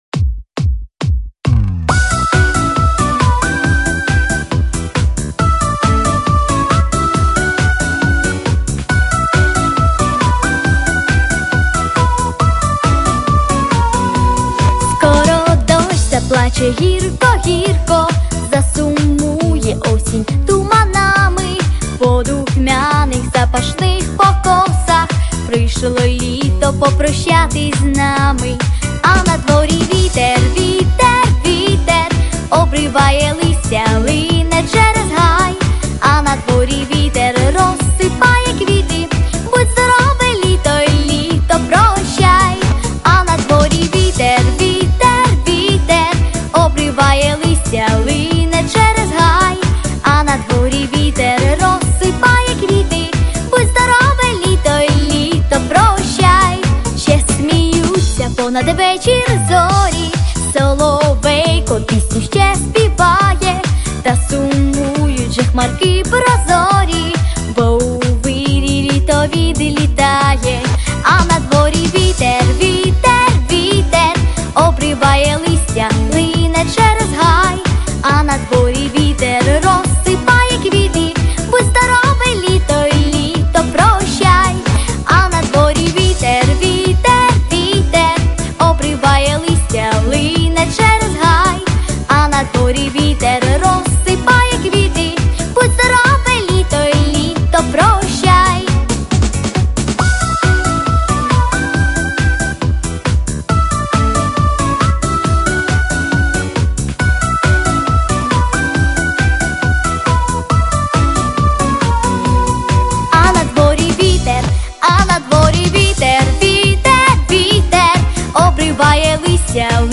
Помірна
Соло
Дитяча